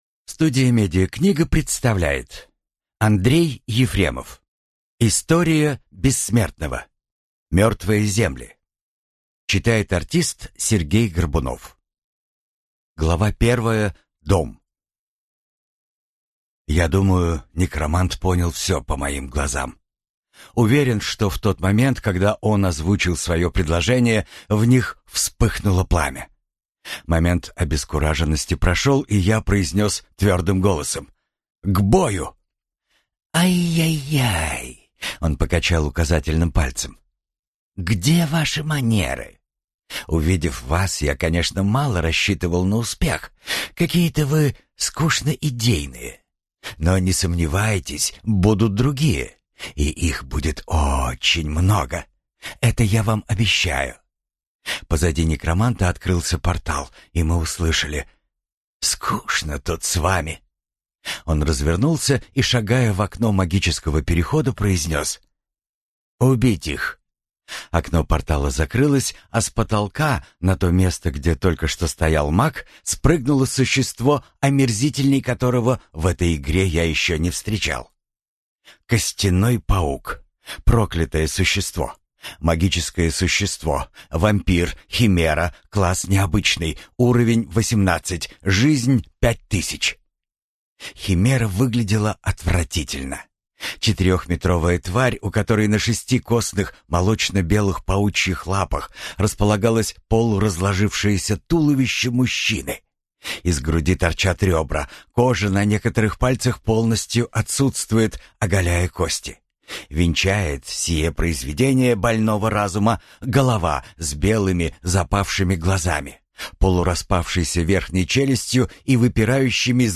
Аудиокнига История Бессмертного. Книга 2. Мертвые земли | Библиотека аудиокниг